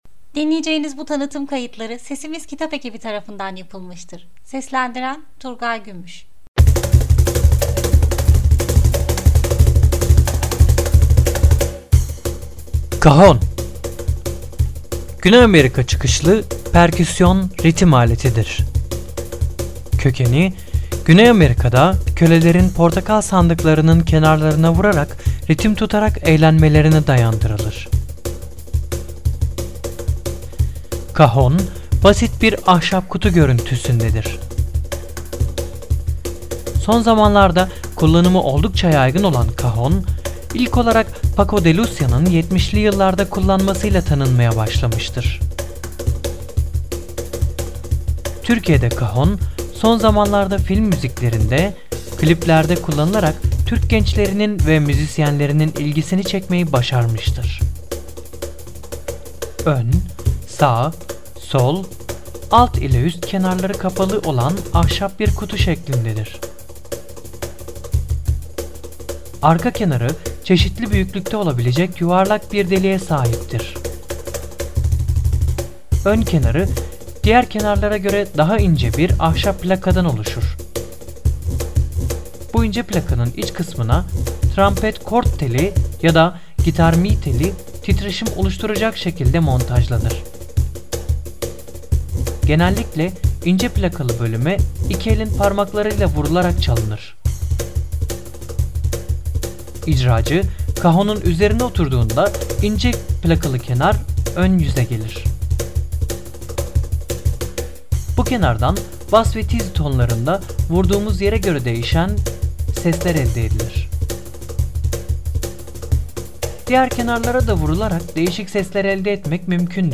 Kahon